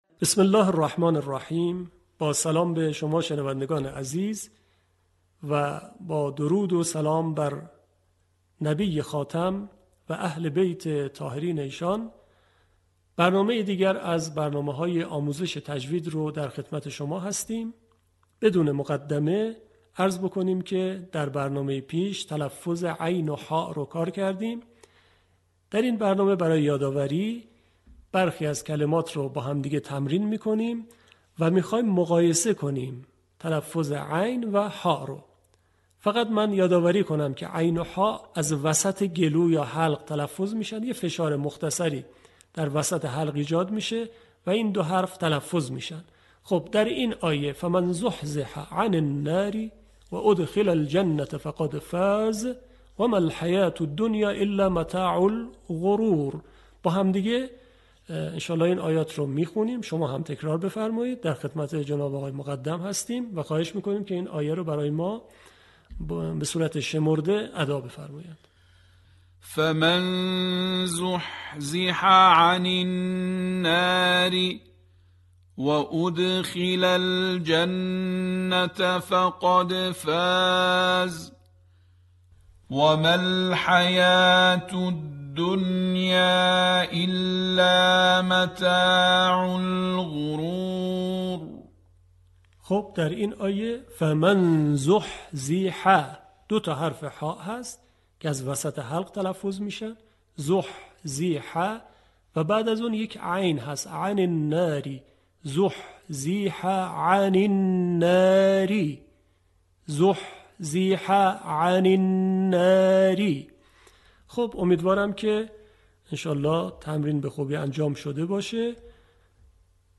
برچسب ها: آموزش قرآن ، آموزش تجوید ، پای رحل قرآن